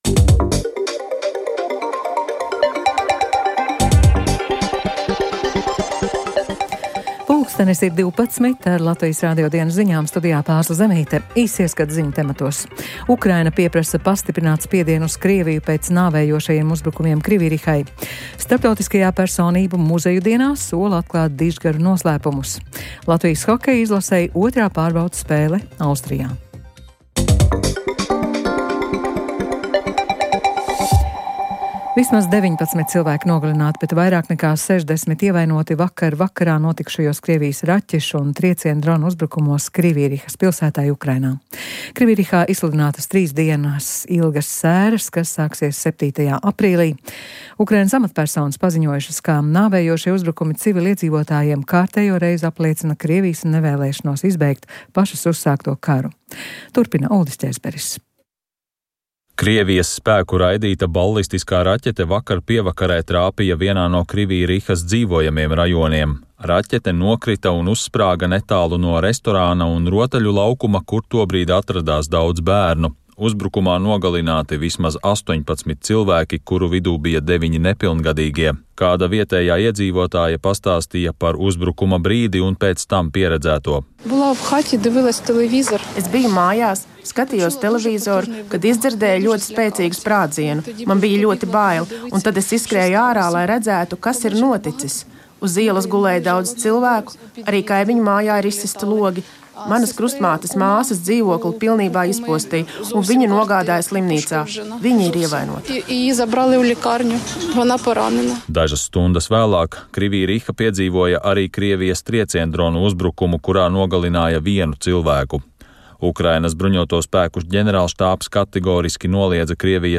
Dienas svarīgāko notikumu apskats – Latvijas Radio Ziņu dienesta korespondenti ir klāt vietās, kur tiek pieņemti lēmumi un risinās notikumi. Podkāsts trīs reizes dienā informē par svarīgākajām aktualitātēm Latvijā un ārvalstīs.